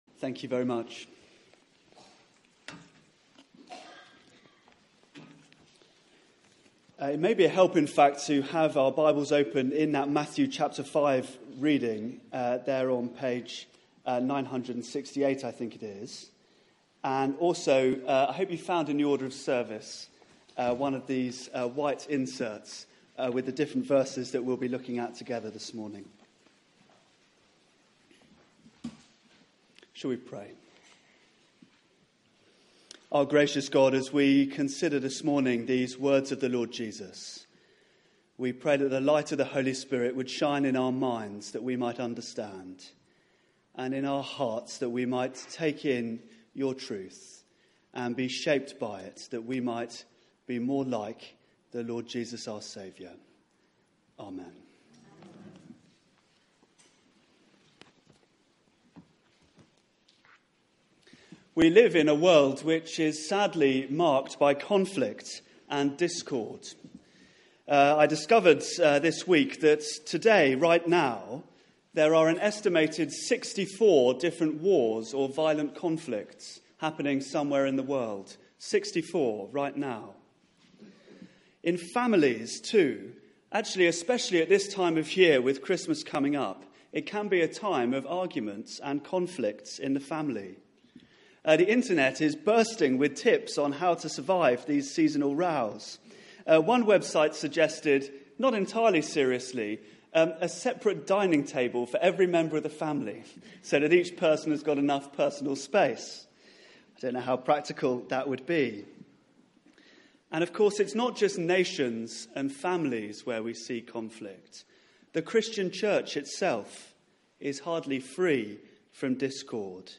Media for 11am Service on Sun 27th Nov 2016
Series: The Beatitudes Theme: Blessed are the peacemakers Sermon